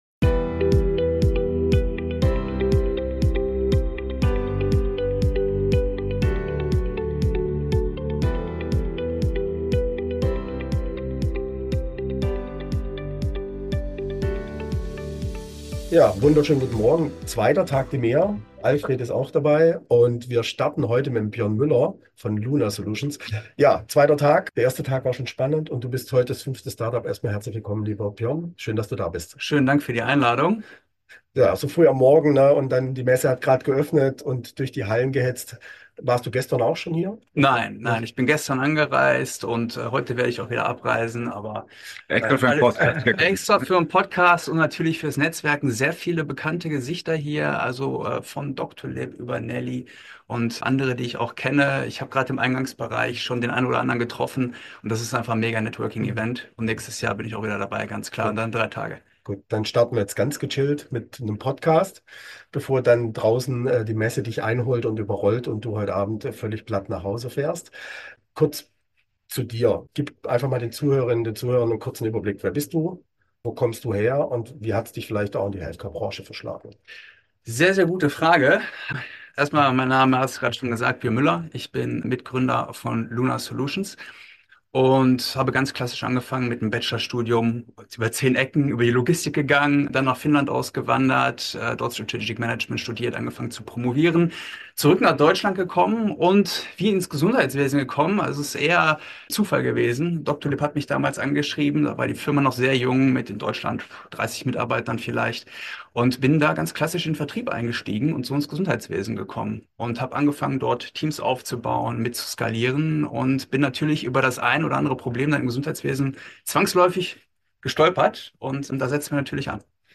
Kompakt Interview 03: Von smartem Recruiting bis hin zu digitaler Patientenmobilität ~ Rocketing Healthcare: Startups & Innovationen im Gesundheitswesen Podcast